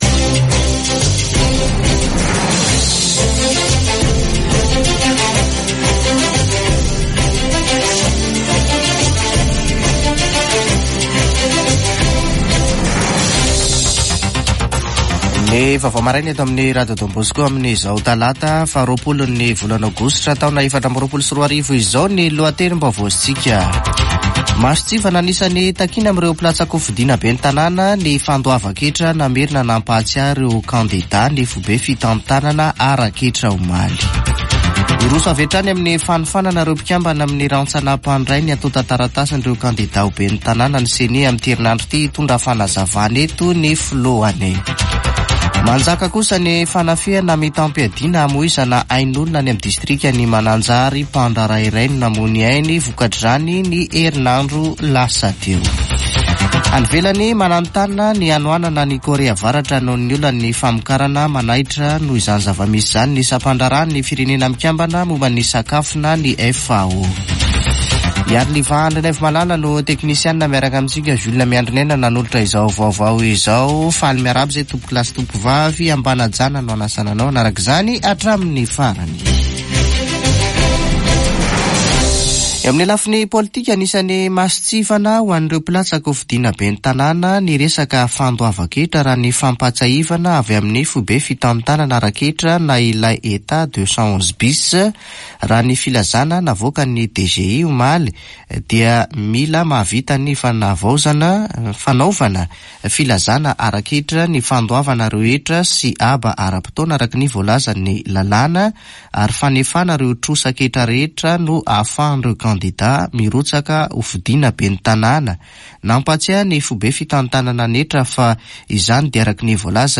[Vaovao maraina] Talata 20 aogositra 2024